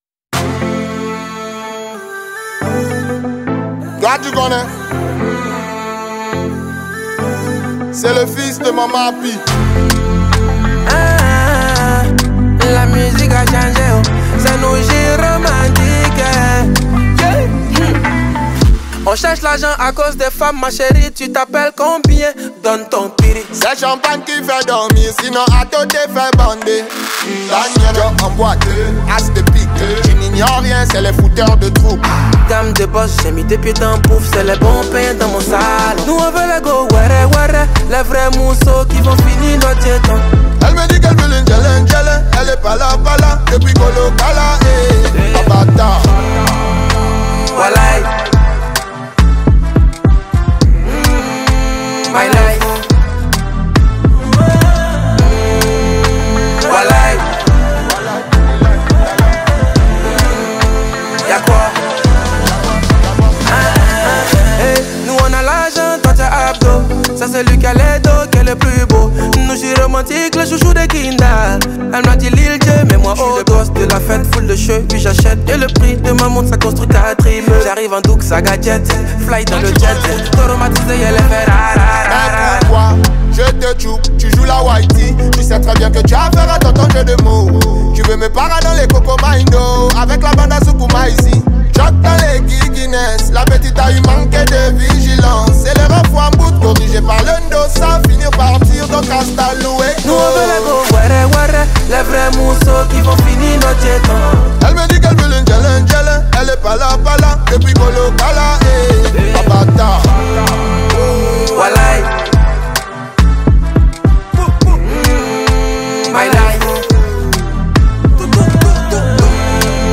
| Rap Hip-Hop